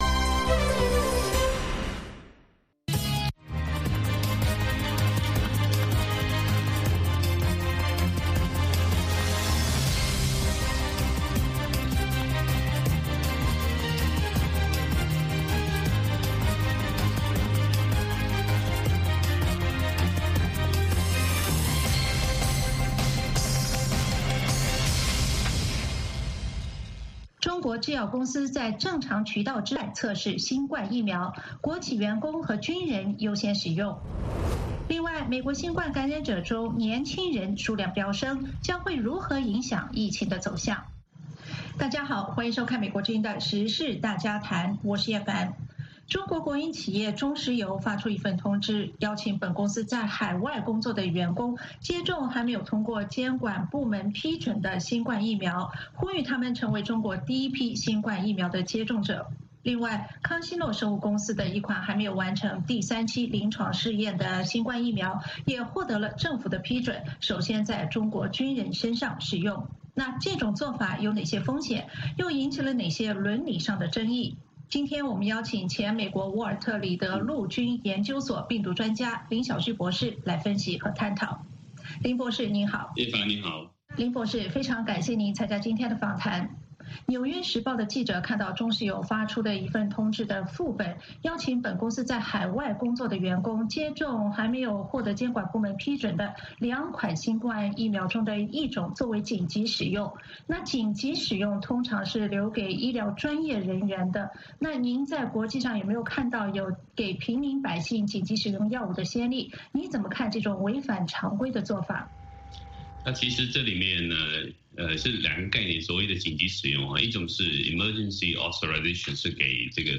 美国之音中文广播于北京时间每天晚上10-11点播出《时事经纬》节目。《时事经纬》重点报道美国、世界和中国、香港、台湾的新闻大事，内容包括美国之音驻世界各地记者的报道，其中有中文部记者和特约记者的采访报道，背景报道、世界报章杂志文章介绍以及新闻评论等等。